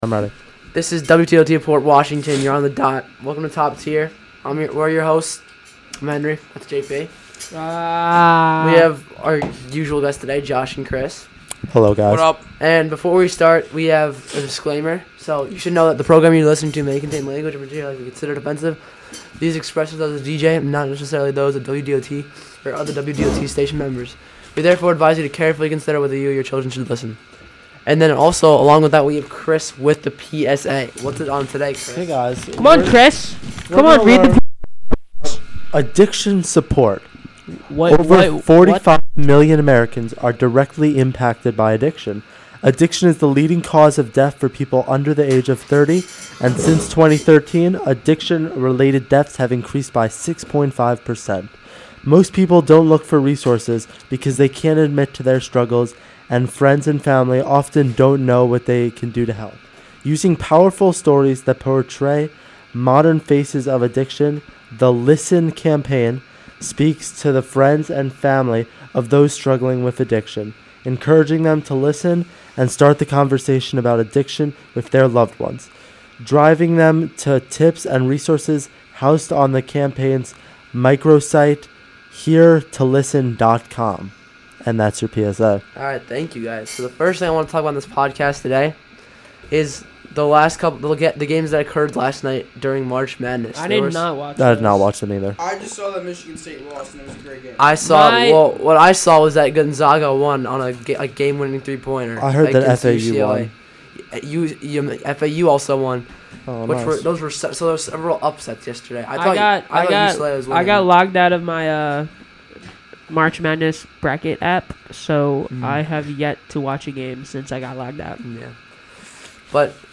Music used is incidental or background clips, in accordance of 37 CFR 380.2. of the US Copyright Law.